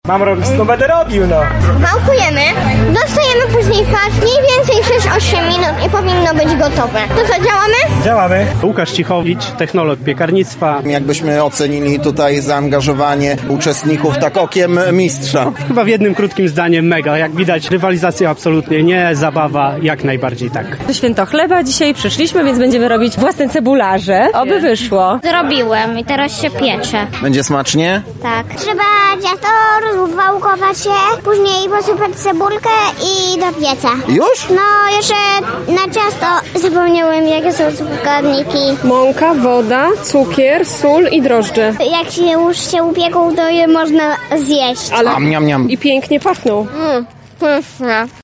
Koncerty kapel ludowych, warsztaty kulinarne, a także jarmark i degustacja tradycyjnych wyrobów regionalnych – w lubelskim skansenie zorganizowano Święto Chleba.